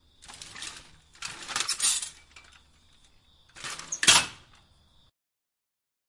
家庭 " 橱柜门锁
描述：在厨房里用钥匙关和锁橱柜门。OKM双耳，Marantz PMD671。降噪。
Tag: 关闭 橱柜 钥匙